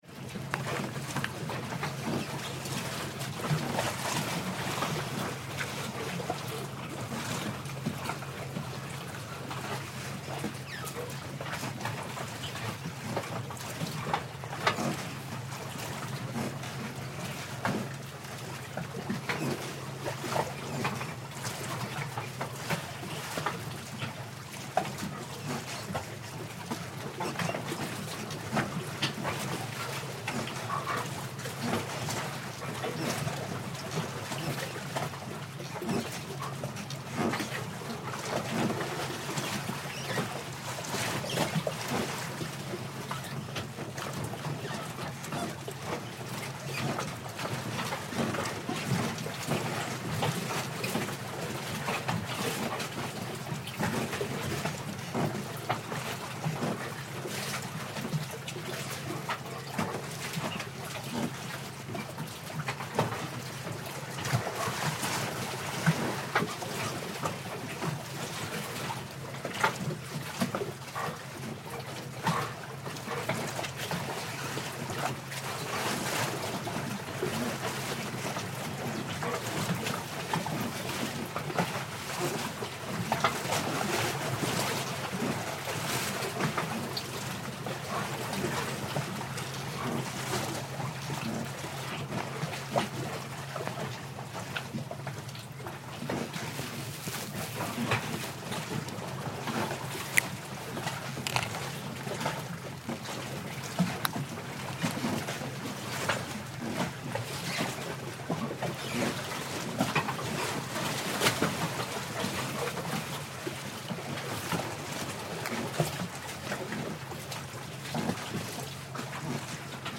Butterworth harbour pier, Malaysia